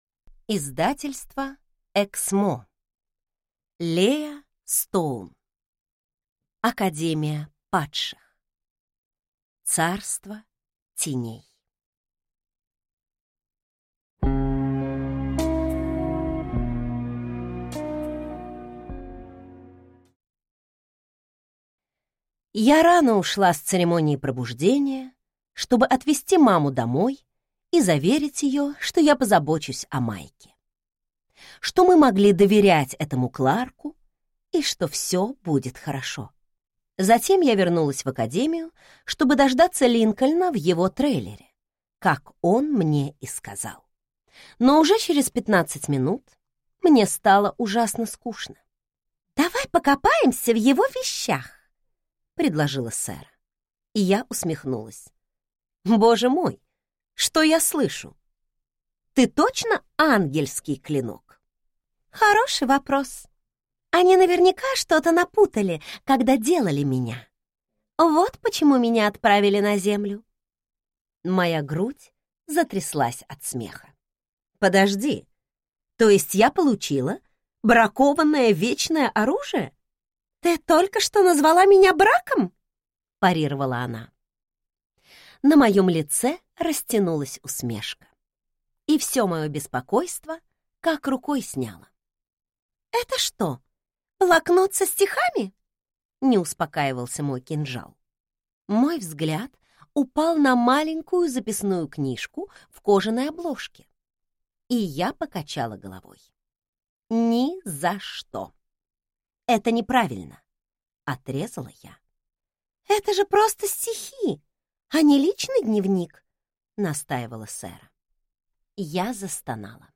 Аудиокнига Царство теней | Библиотека аудиокниг